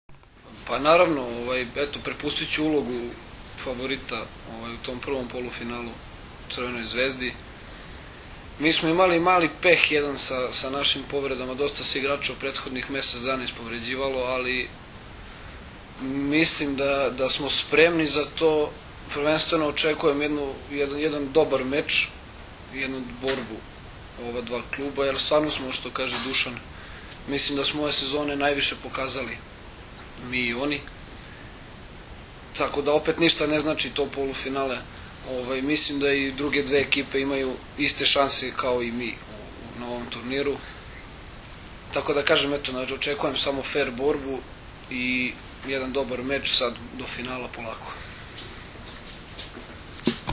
U prostorijama Odbojkaškog saveza Srbije danas je održana konferencija za novinare povodom Finalnog turnira 49. Kupa Srbije u konkurenciji odbojkaša, koji će se u subotu i nedelju odigrati u Hali sportova u Kraljevu.